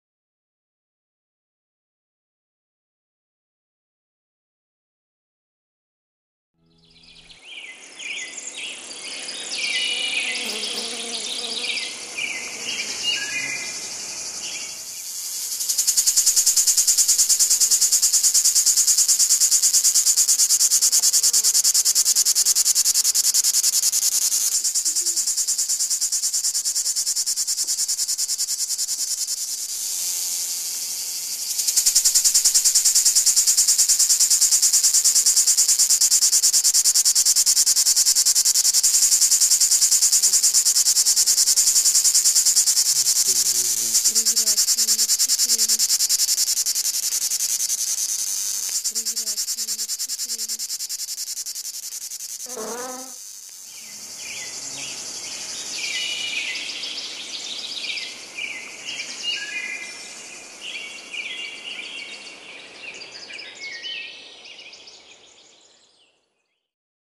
Звуки цикады
На этой странице собраны звуки цикад — от монотонного стрекотания до интенсивного хора насекомых.
Шепот цикады